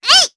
Lilia-Vox_Attack4_jp.wav